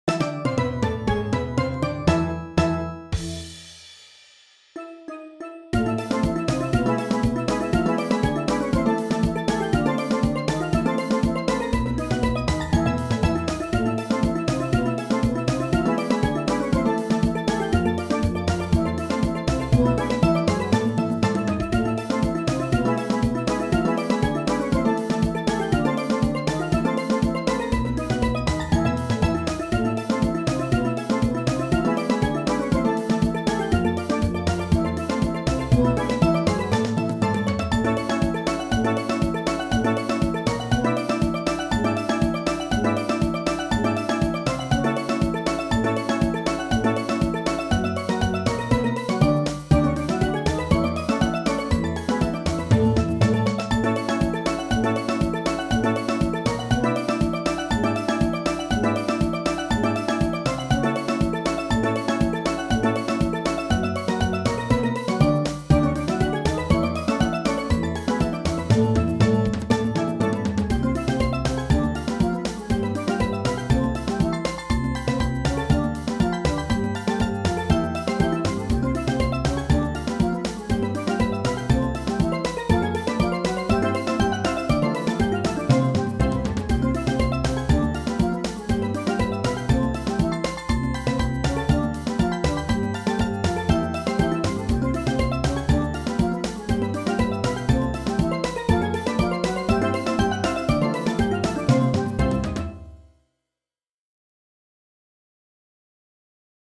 Voicing: Steel Drum